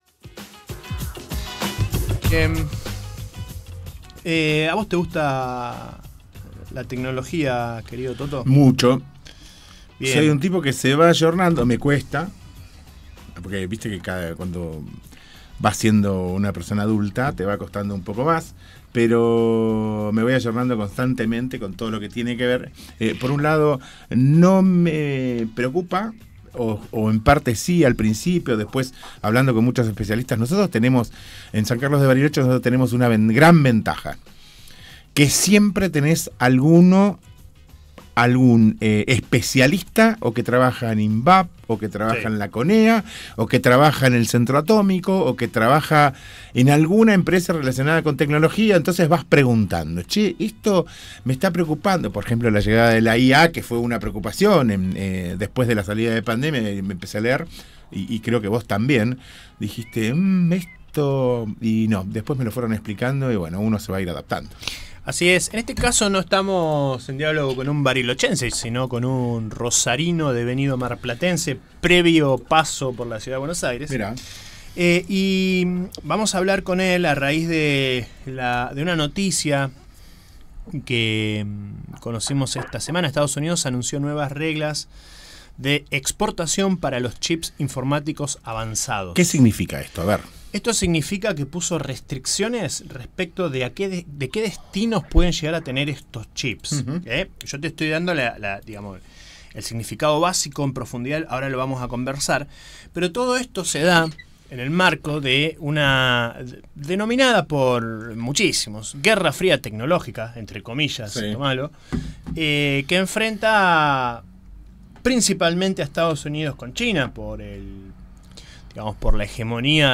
Segunda Entrevista en Radio Con Vos Patagonia: políticas comerciales, industrias tecnológicas y regulaciones de las IA en EE.UU.